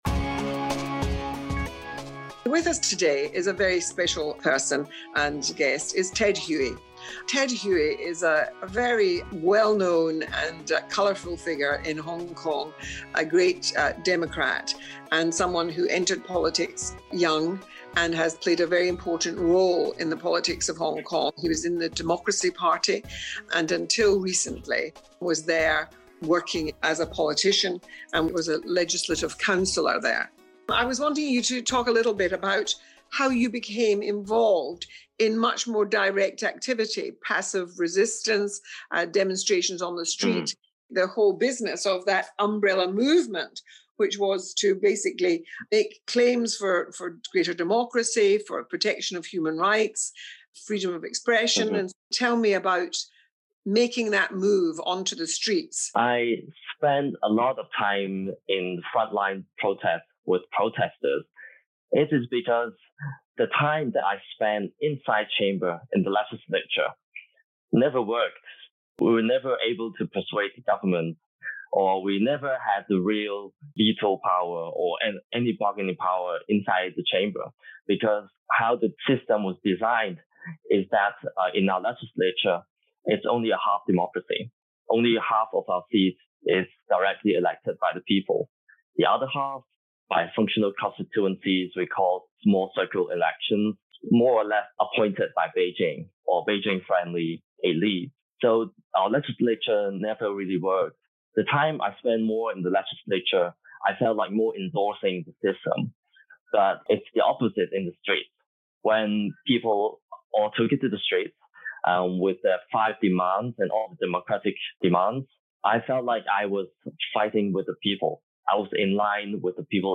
Baroness Helena Kennedy QC, Director of the IBA's Human Rights Institute, speaks to former legislator Ted Hui, who fled Hong Kong for the United Kingdom at the end of 2020.